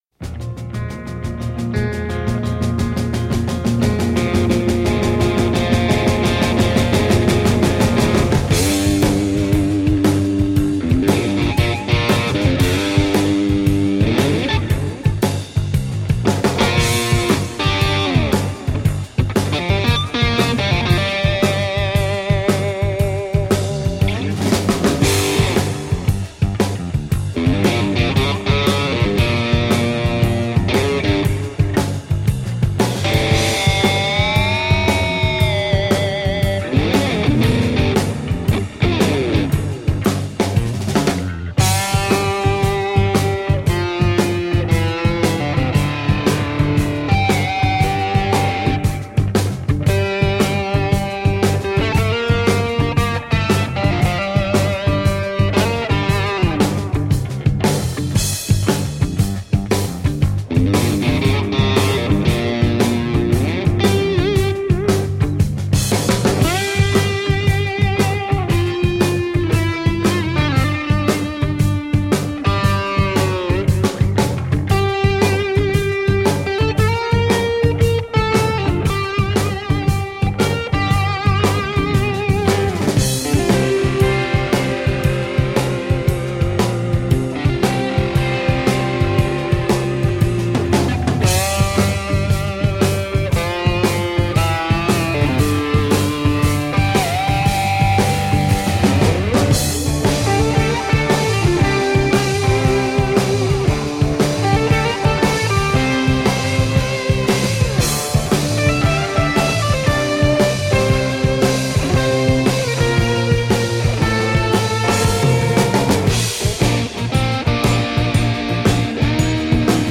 Evocative, melodic and haunting instrumental guitar music.